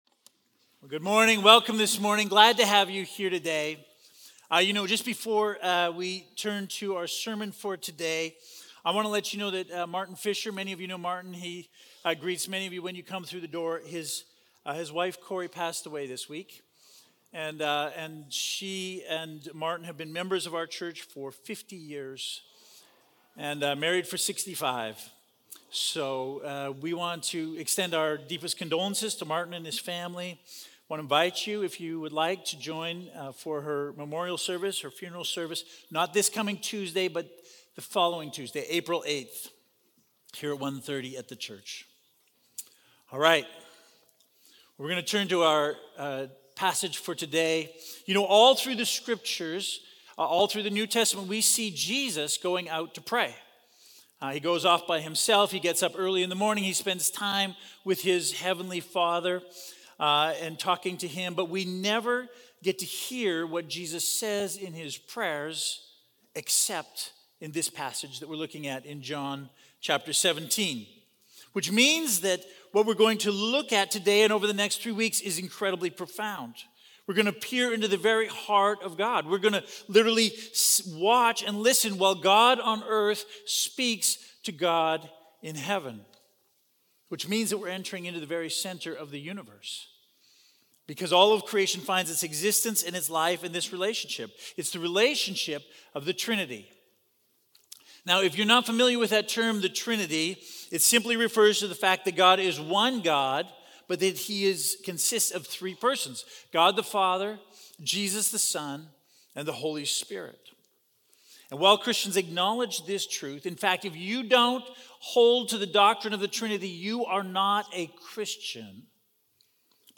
Sermons | Ridge Church